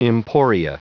Prononciation du mot emporia en anglais (fichier audio)
Prononciation du mot : emporia